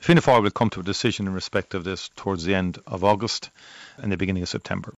Taoiseach and party leader Micheál Martin would only say this when asked about Fianna Fáil’s plans…………..